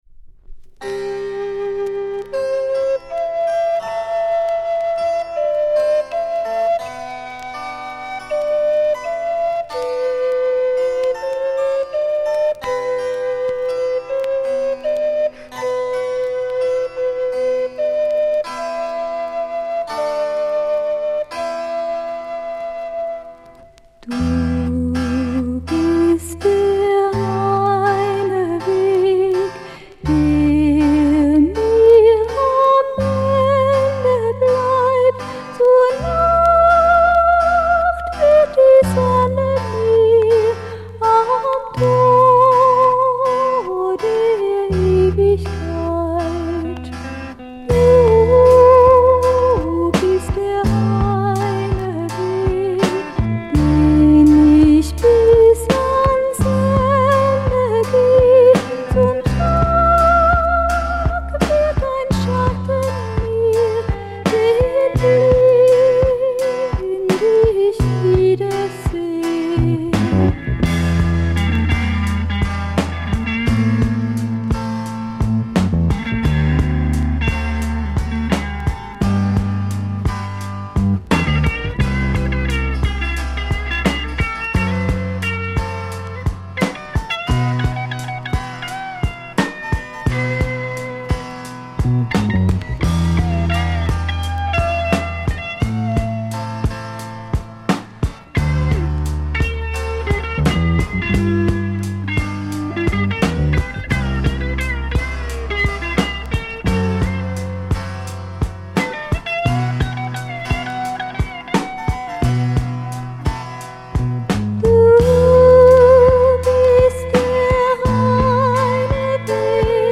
Baroque Female vocal Kraut Psych